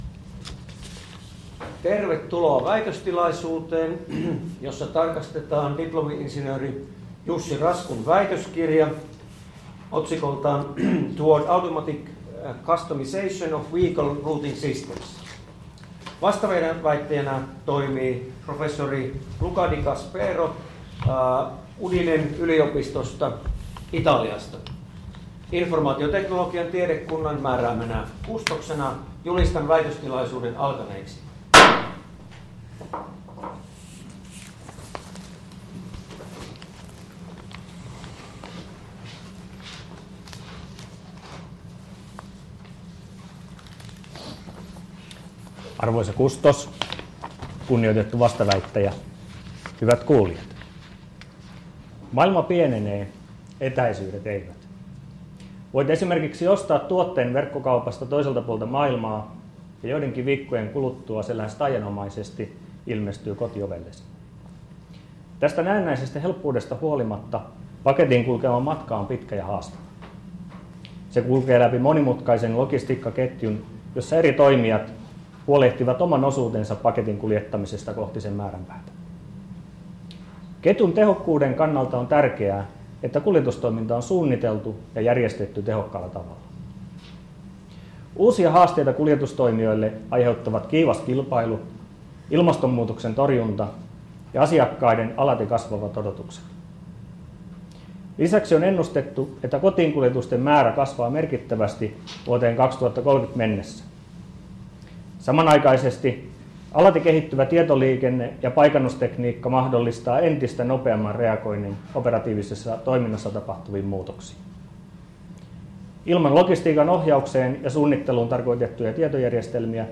Toward Automatic Customization of Vehicle Routing Systems” tarkastustilaisuus pidetään 1. marraskuuta 2019 klo 12 alkaen Agoran Lea Pulkkisen salissa (Ag B431.1, 4krs).